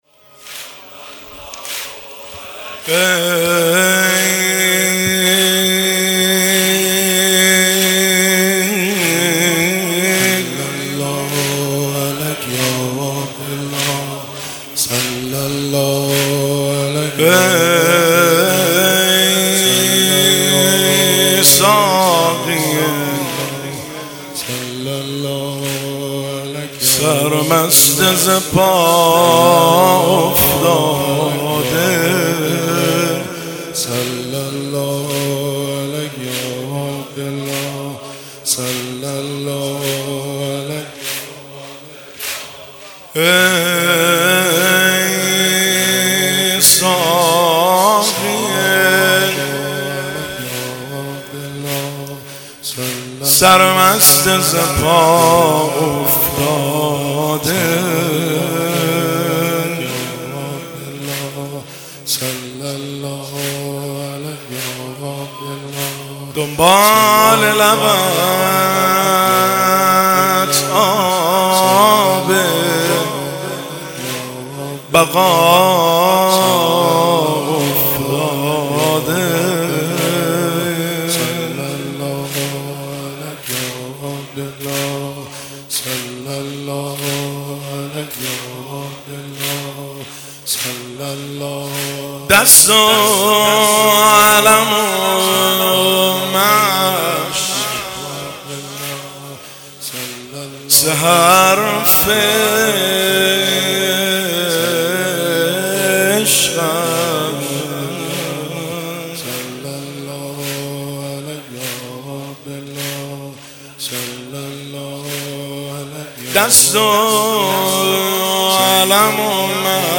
تاسوعا حسینی